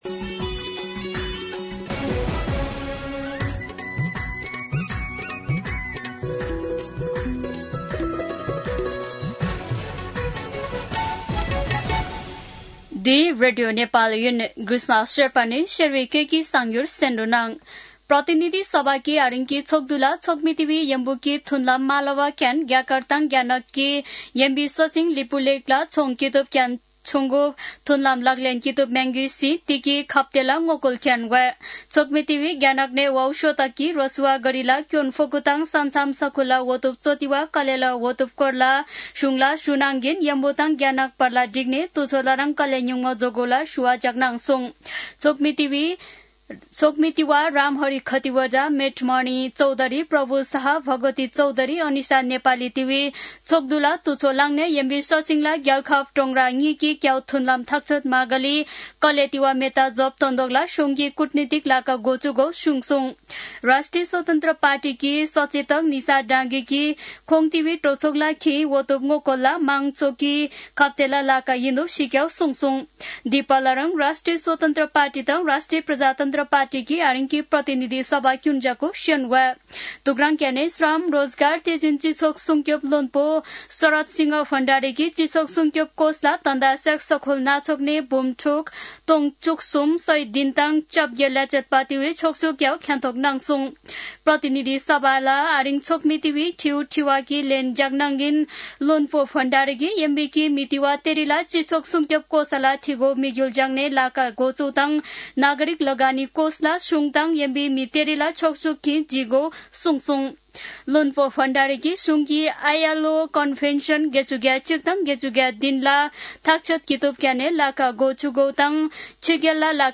शेर्पा भाषाको समाचार : ६ भदौ , २०८२
Sherpa-News-1-3.mp3